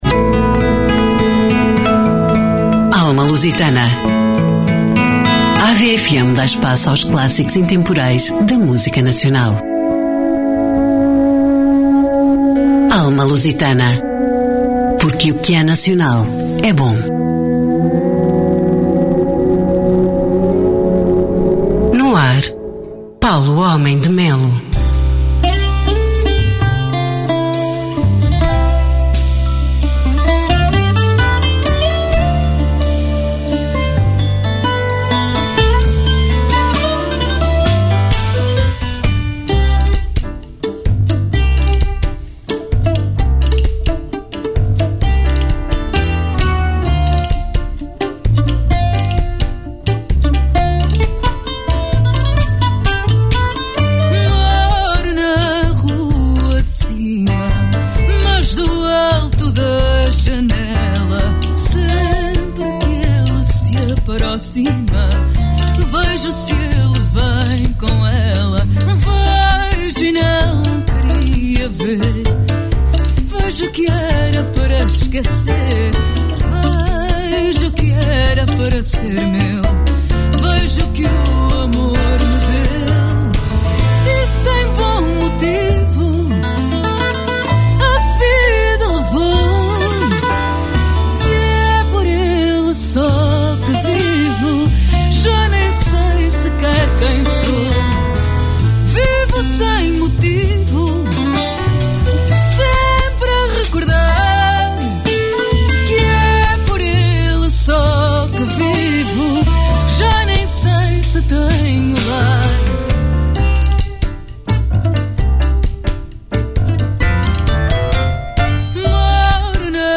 Músicas com Portugal na alma